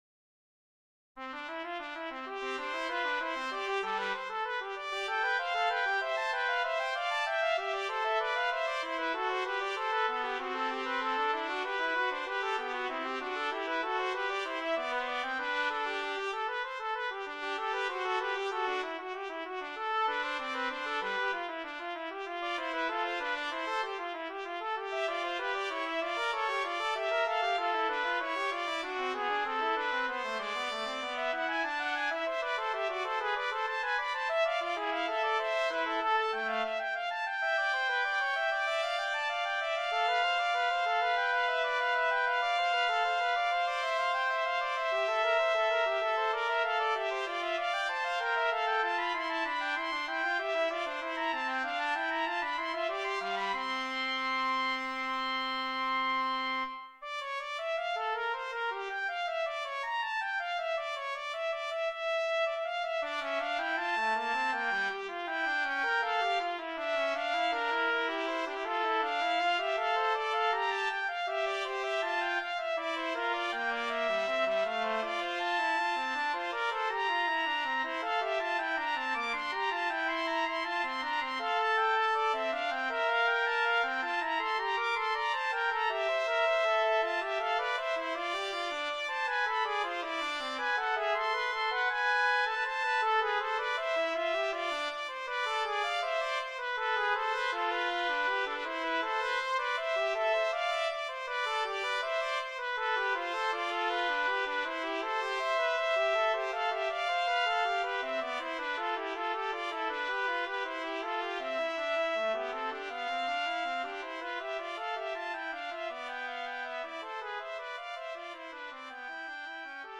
Voicing: Trumpet Duet